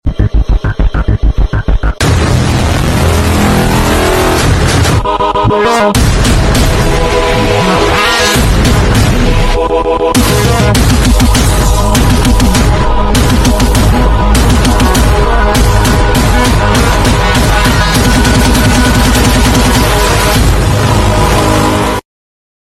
Spotted the new Tesla Roadster in Florida today.